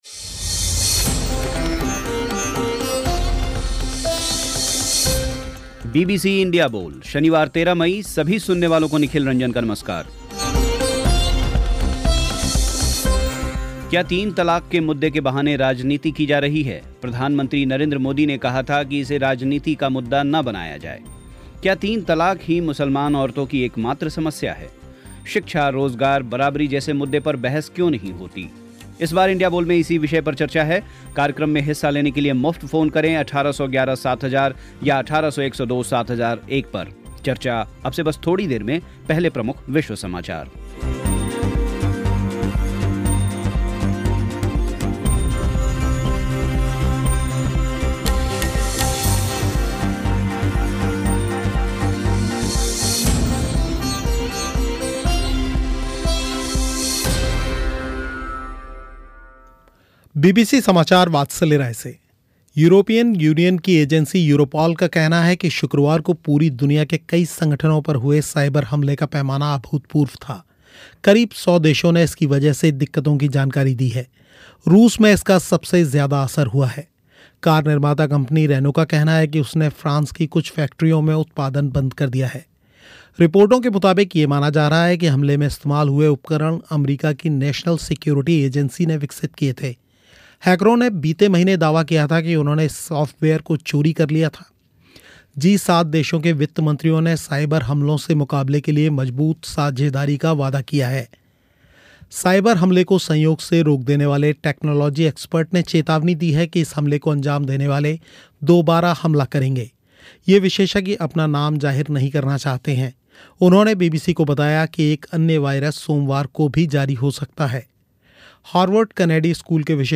क्या तीन तलाक़ ही मुसलमान औरतों की एकमात्र समस्या है? शिक्षा, रोज़गार, बराबरी जैसे मुद्दे पर बहस क्यों नहीं होती? आज इंडिया बोल में इसी विषय पर चर्चा